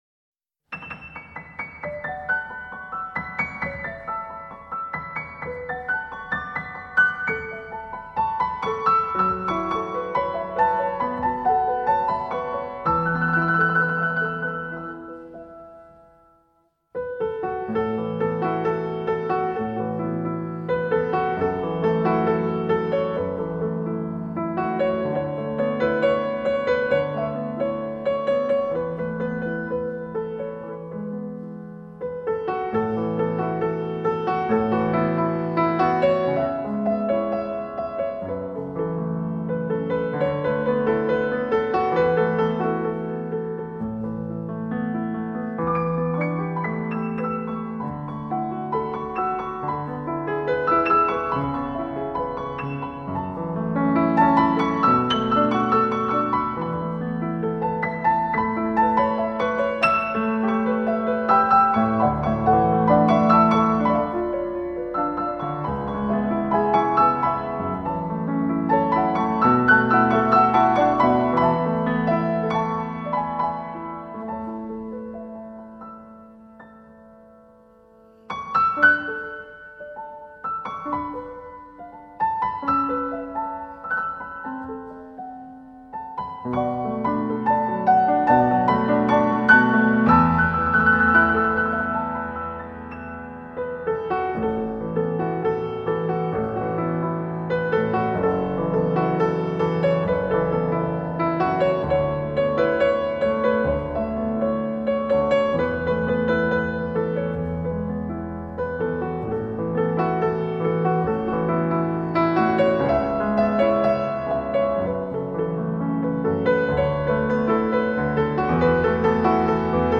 钢琴独奏
聆听青年钢琴家的钢琴心语